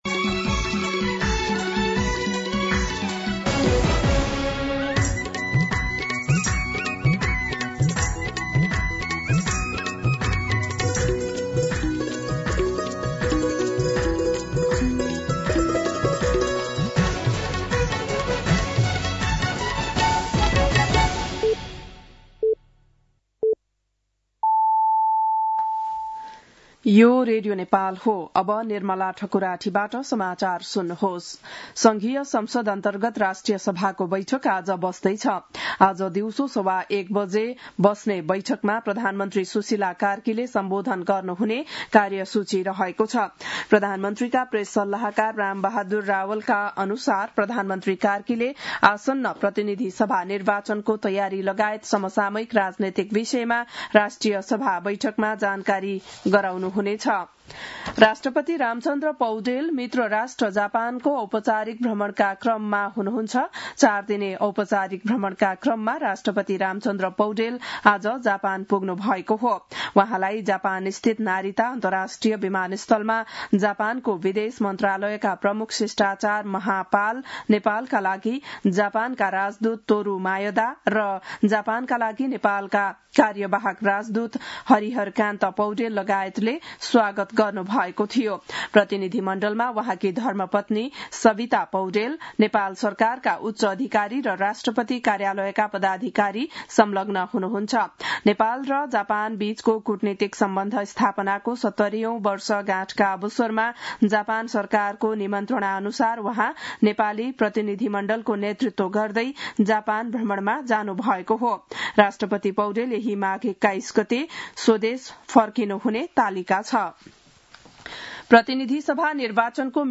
बिहान ११ बजेको नेपाली समाचार : १९ माघ , २०८२